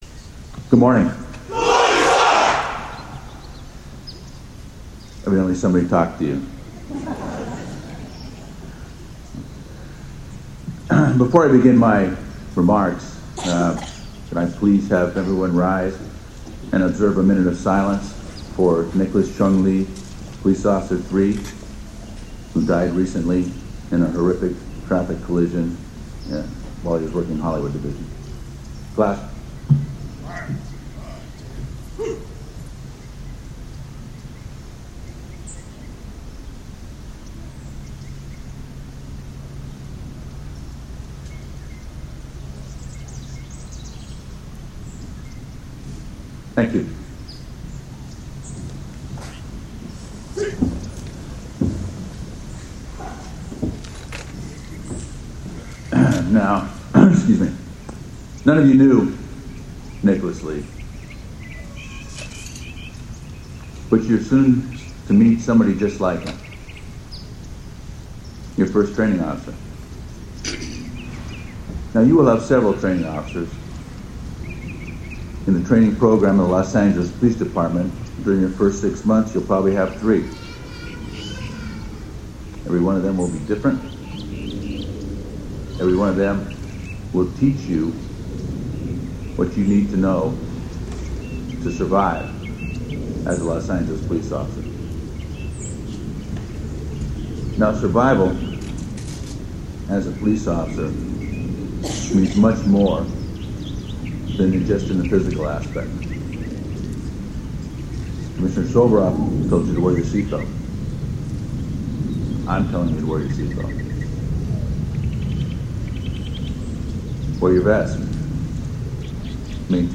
Twenty-one individuals underwent six months of intense training which culminated with today’s ceremony in front of Police Chief Charlie Beck, Department command staff, dignitaries and their family and friends.
For audio of Chief Beck's speech click here.